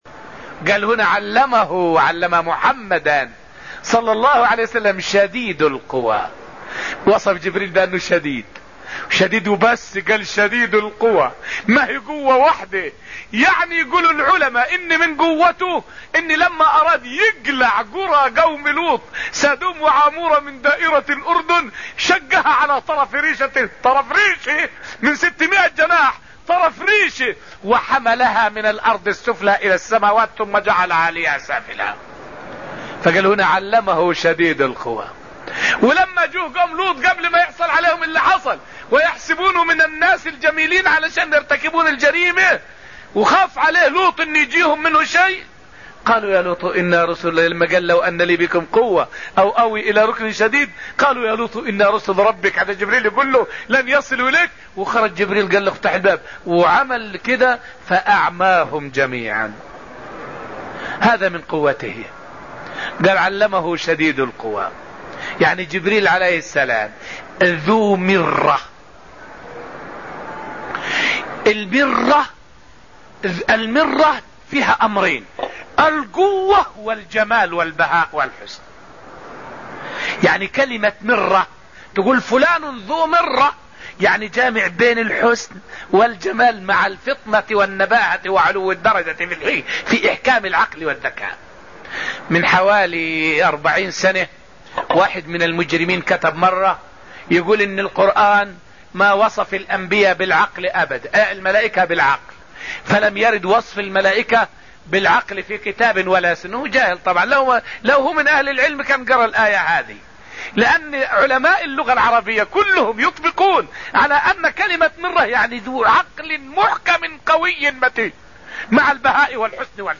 فائدة من الدرس الرابع من دروس تفسير سورة النجم والتي ألقيت في المسجد النبوي الشريف حول أمين الوحي .. جبريل عليه السلام.